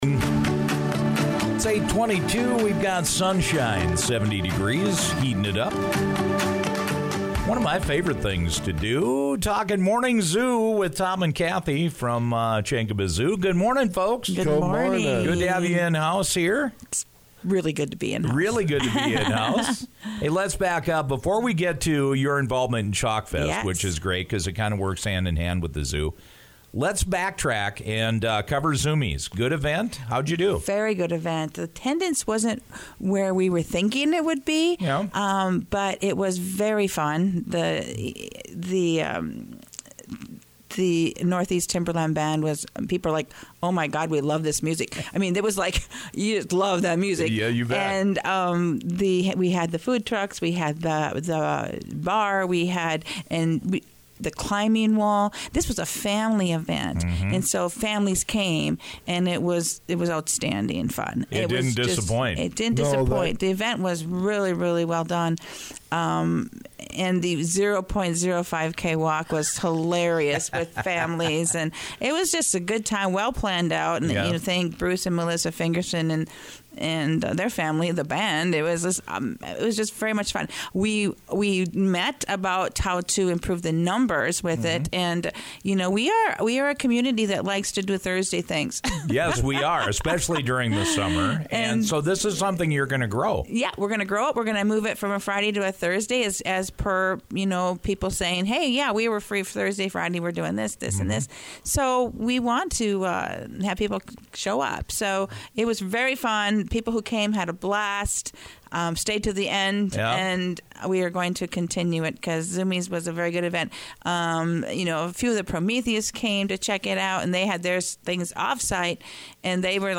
This week the Zoo digs in to Chalkfest with habitat coloring and mandalas. Listen to our conversation below.